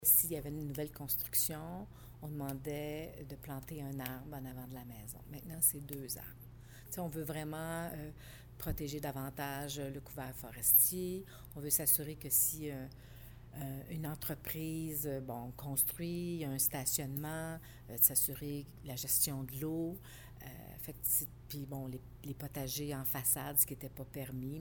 La mairesse de Cowansville, Sylvie Beauregard, donne quelques exemples sur ce qui pourrait être fait :